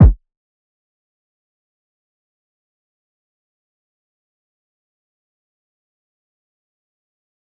DMV3_Kick 1.wav